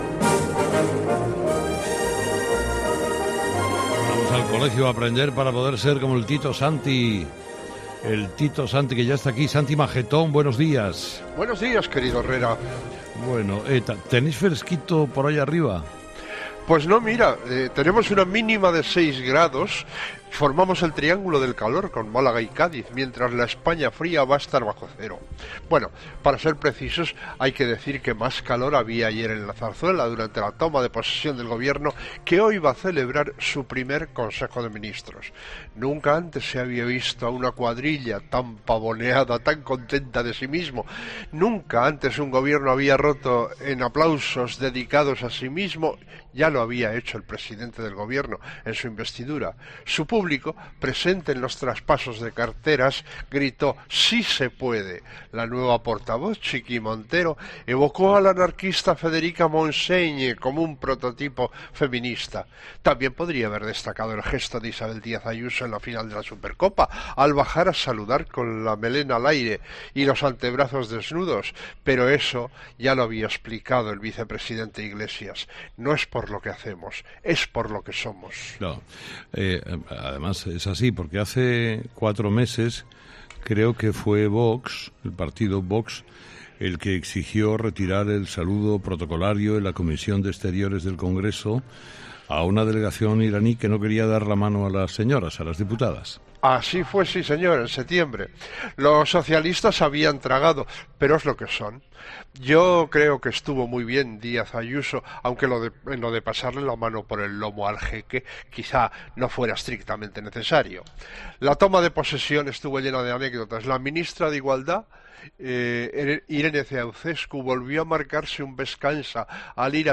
No te pierdas los sonidos que este martes 13 de enero han marcado el espacio dirigido y presentado por Carlos Herrera desde el Acuario de Sevilla